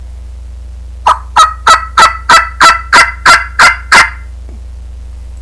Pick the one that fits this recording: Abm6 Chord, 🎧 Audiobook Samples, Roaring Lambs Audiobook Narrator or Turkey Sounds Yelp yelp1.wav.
Turkey Sounds Yelp yelp1.wav